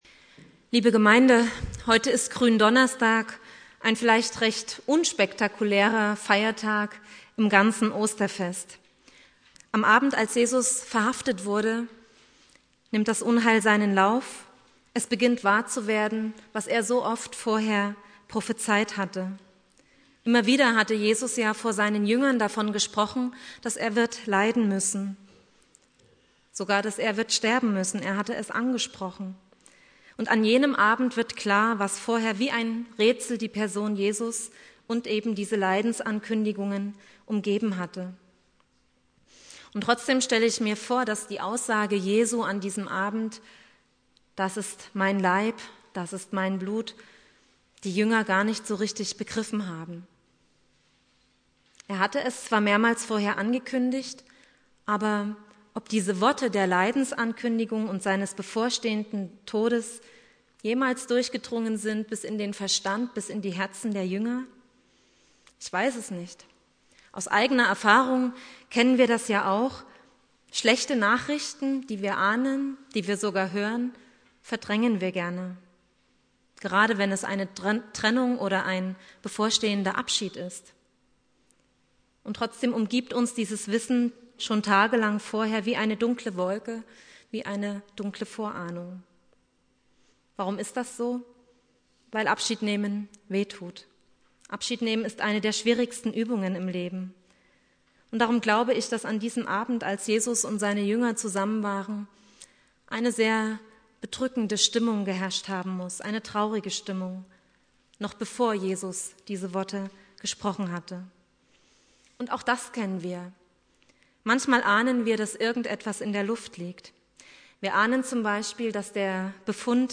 Predigt
Gründonnerstag